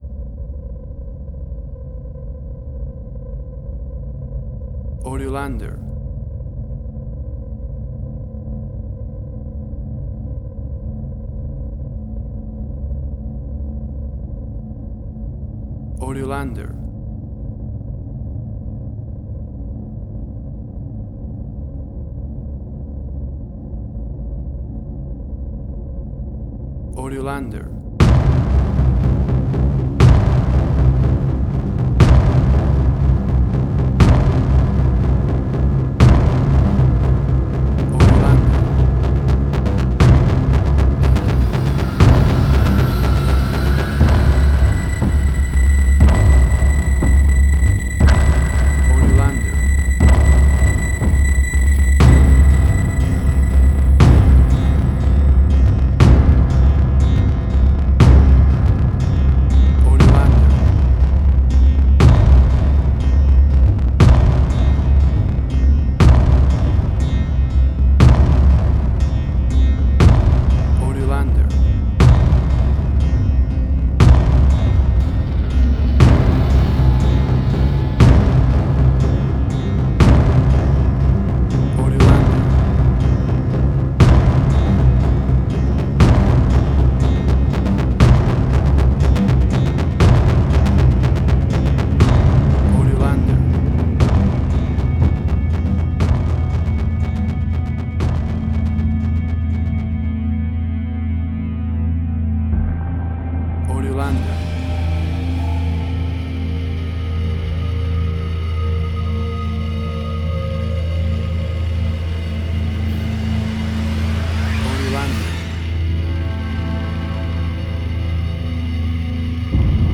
Middle Eastern Fusion.
Tempo (BPM): 120